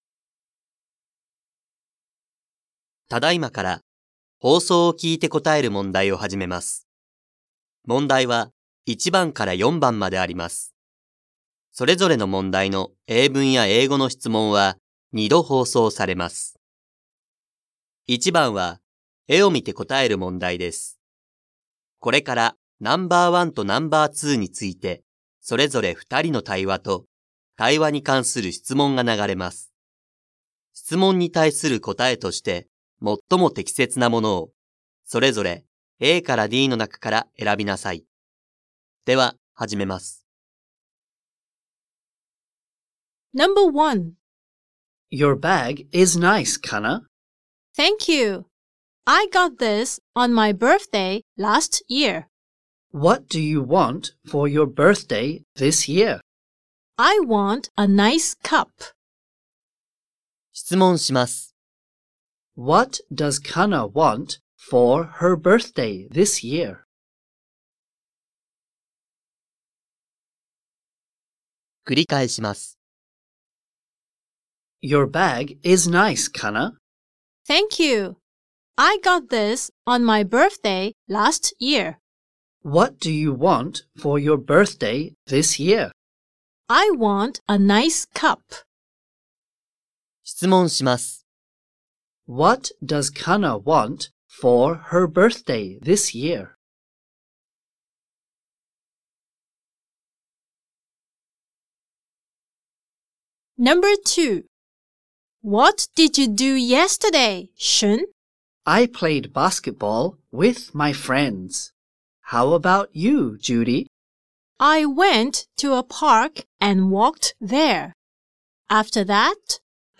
2024年度１年３号英語のリスニングテストの音声